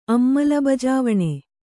♪ ammalabajāvaṇe